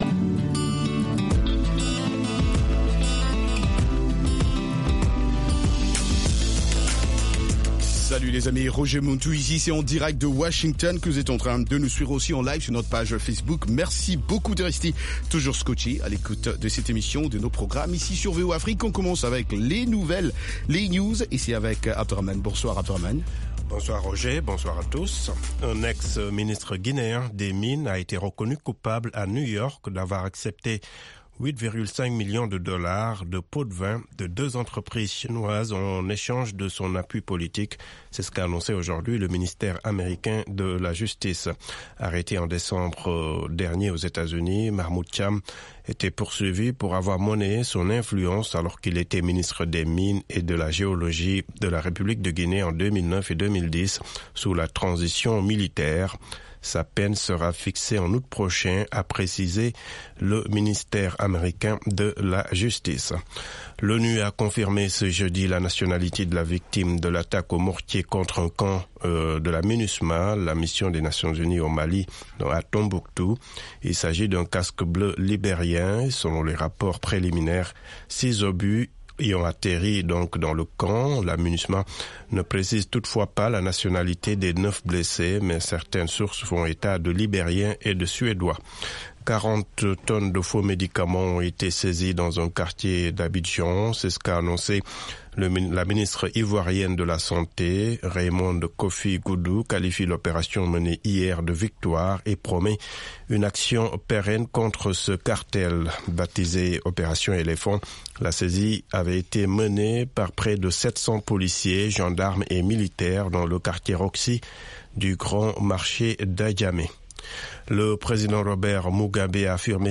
Afro Music